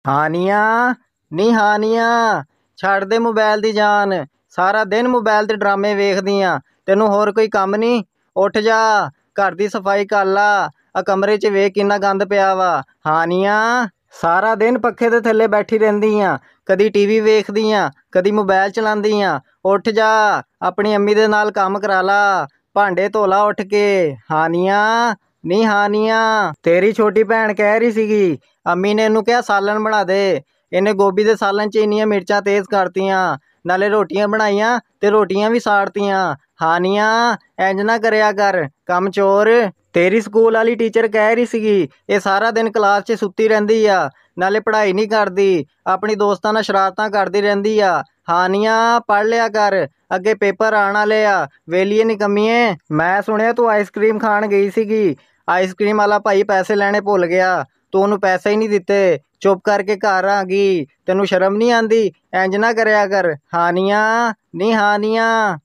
Goat